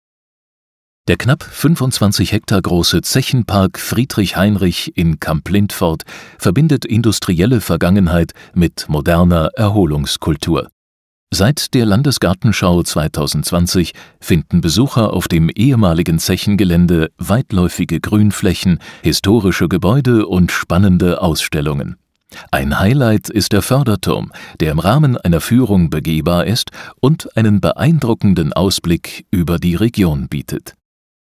audioguide-zechenpark-friedrich-heinrich.mp3